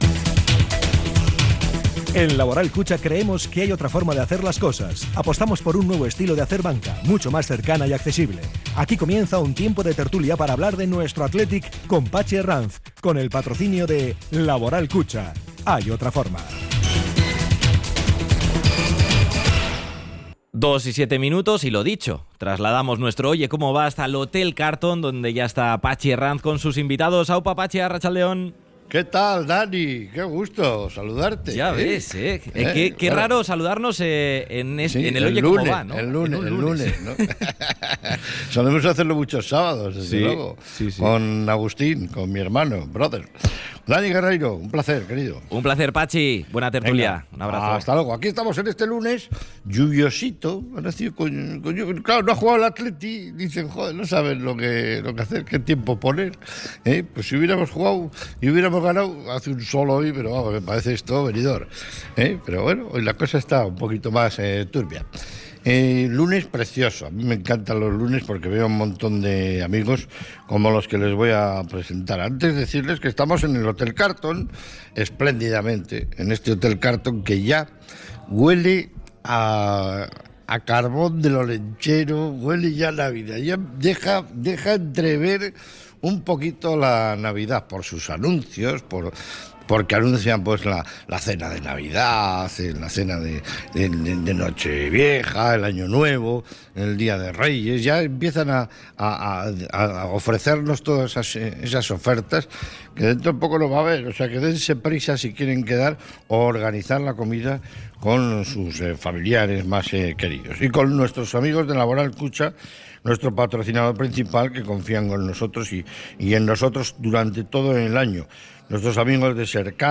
desde el Hotel Carlton
tertulia-athletic.mp3